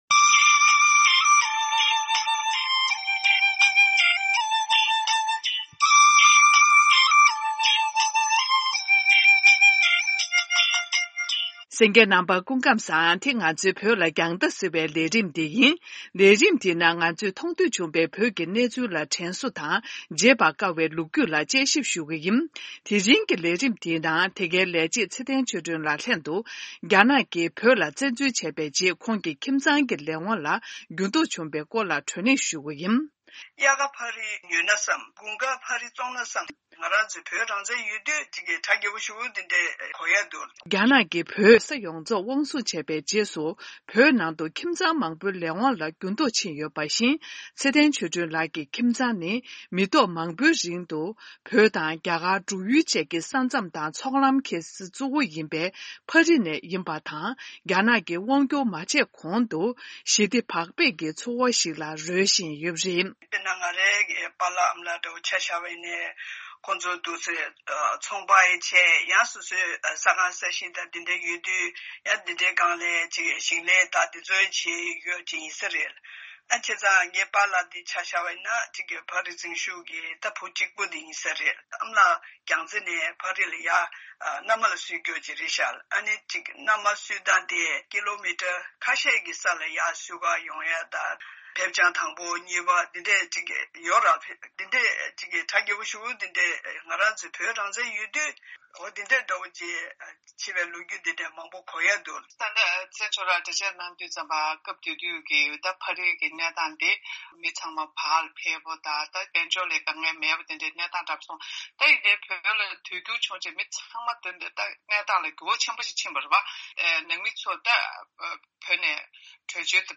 གླེང་མོལ་ཞུས་པའི་དུམ་བུ་དང་པོ་དེ་གསན་རོགས་གནང་།